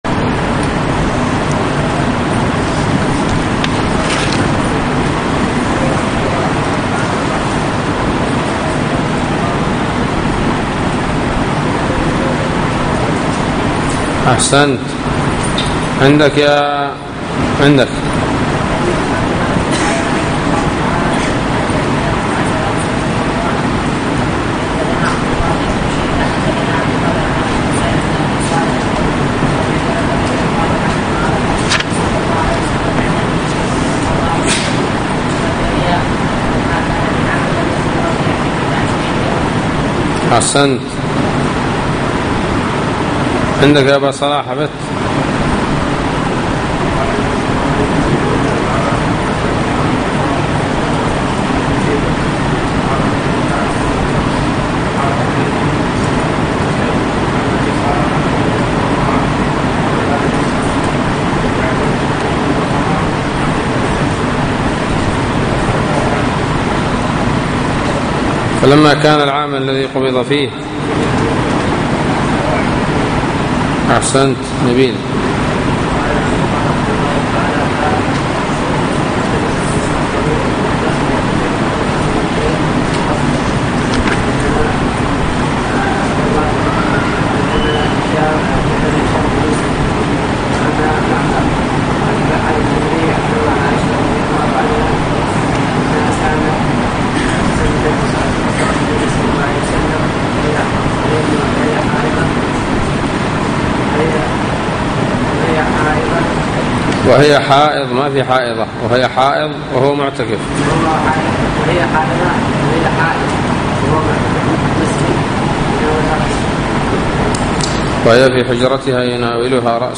الدرس الواحد من كتاب البيوع من صحيح الإمام البخاري